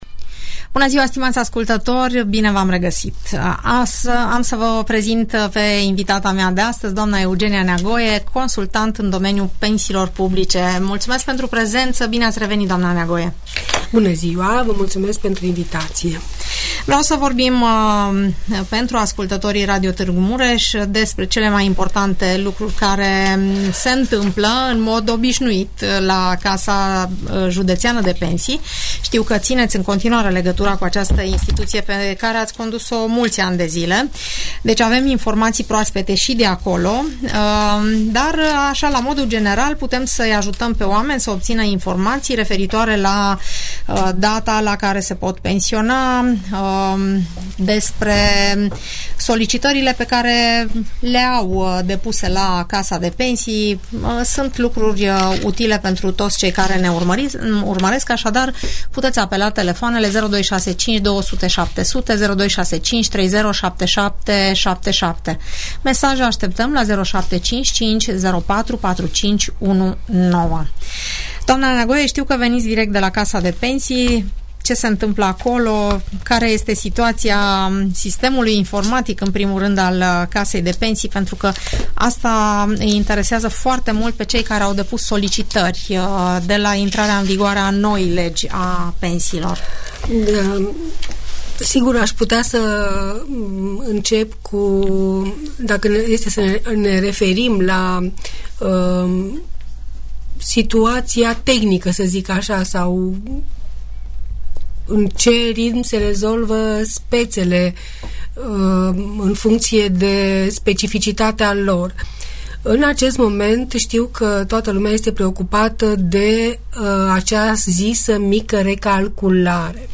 Audiență radio cu întrebări și răspunsuri despre toate tipurile de pensii, în emisiunea "Părerea ta" de la Radio Tg Mureș.